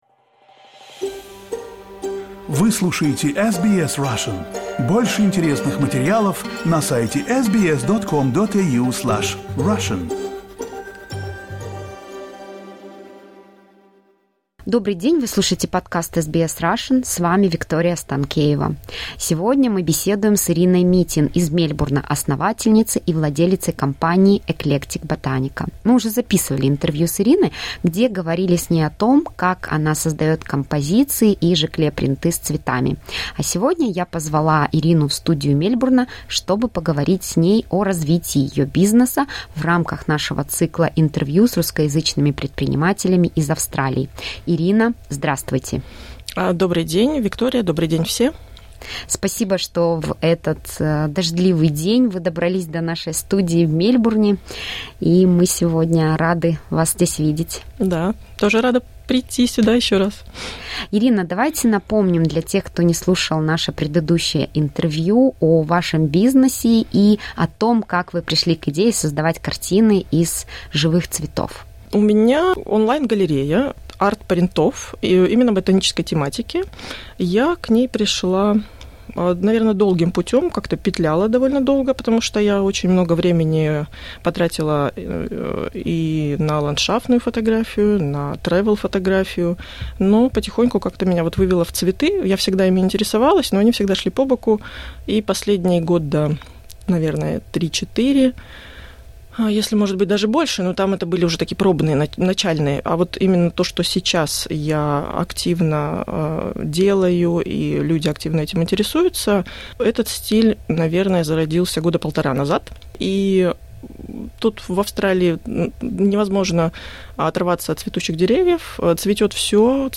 Interview
to the SBS studio